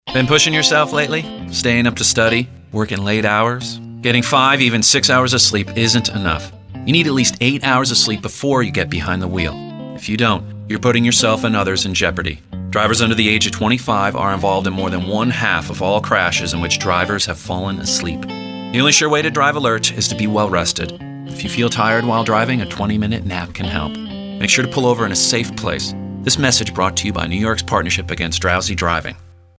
Young Adult :30 Radio PSA.